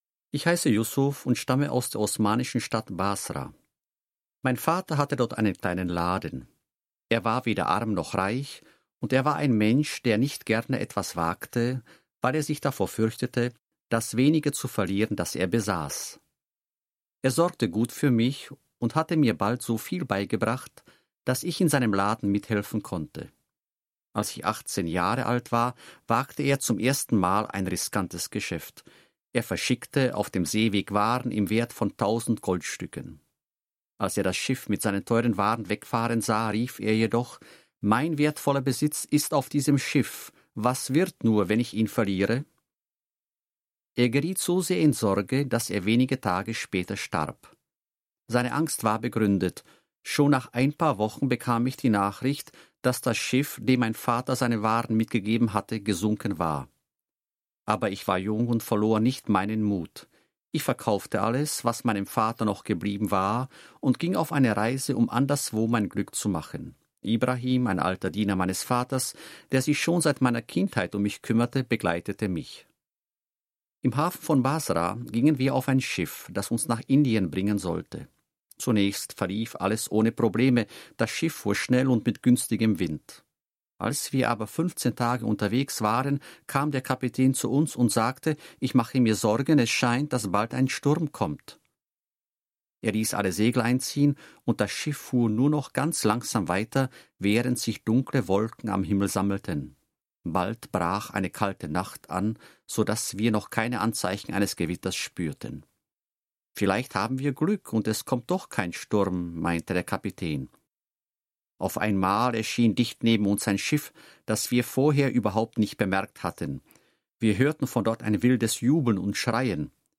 Obsahuje zjednodušené verze hrůzostrašných příběhů z 19. století, které pocházejí od různých německých autorů. Text nahráli rodilí mluvčí v podobě dialogu i monologu.
Audio kniha